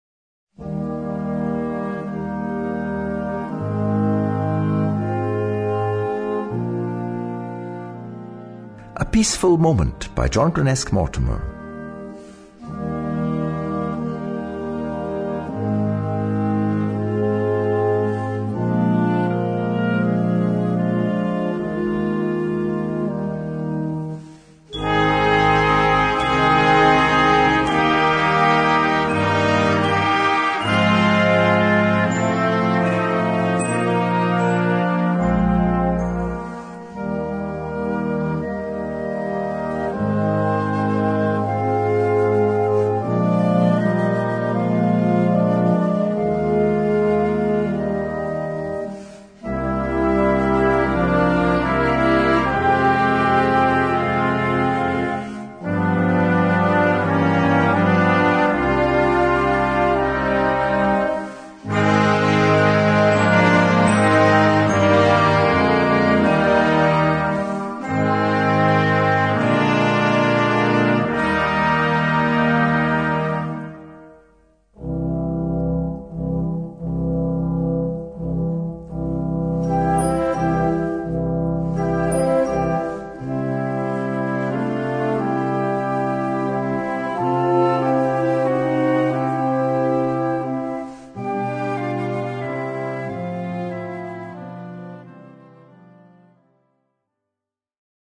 Noten für Blasorchester.